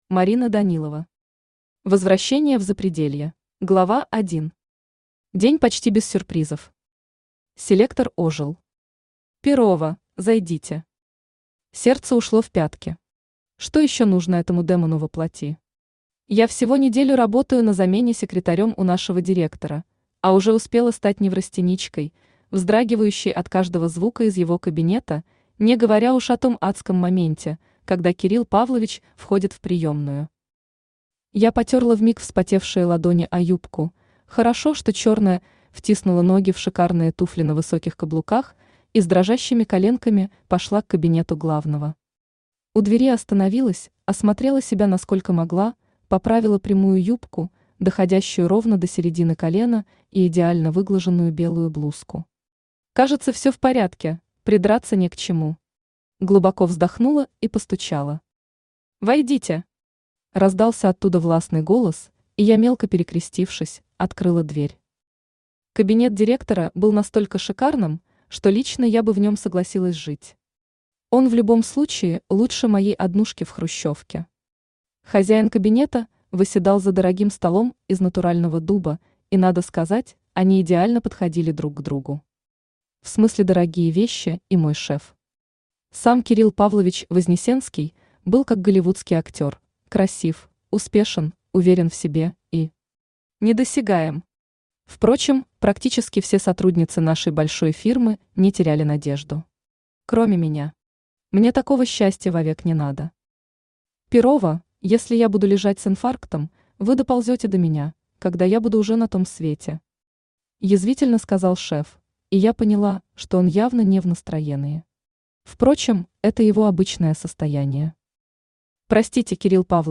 Аудиокнига Возвращение в Запределье | Библиотека аудиокниг
Aудиокнига Возвращение в Запределье Автор Марина Данилова Читает аудиокнигу Авточтец ЛитРес.